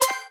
menuhit.wav